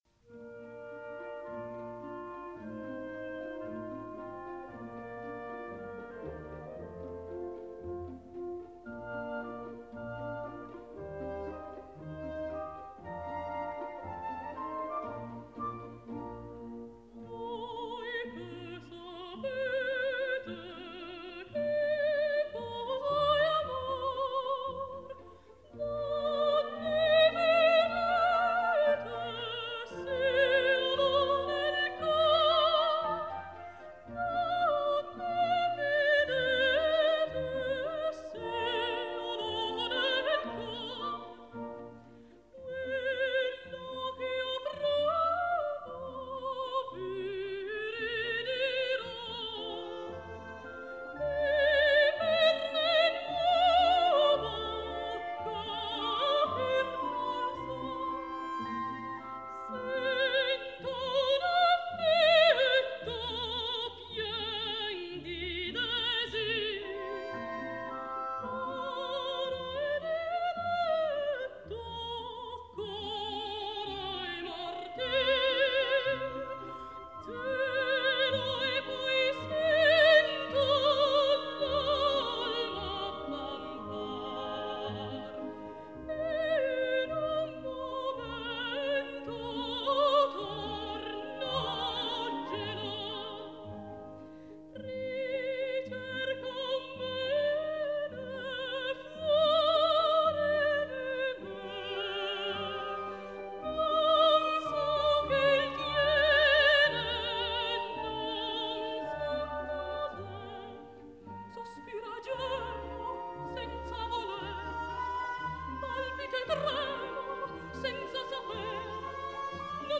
Сегодня исполняется 78 лет итальянской певице ( меццо-сопрано) Фьоренце Коссотто ( род.22 апреля 1935 г)